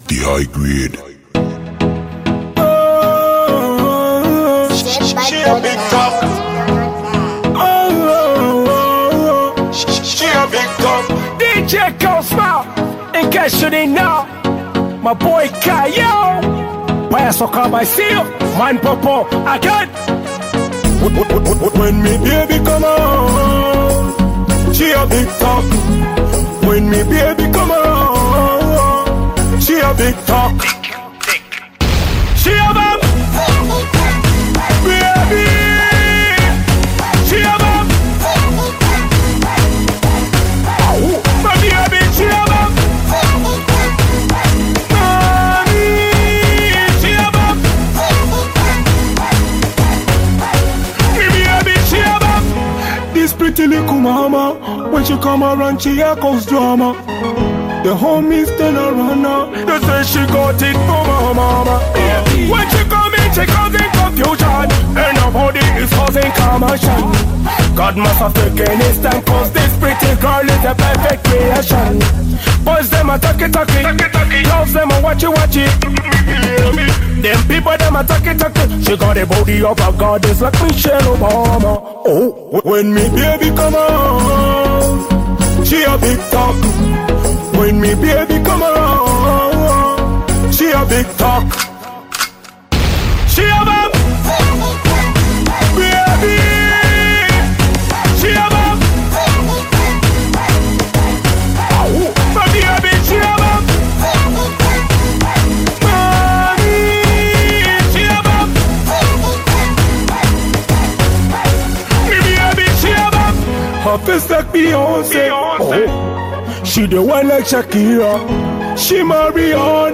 The style of music is perfect for parties or working out.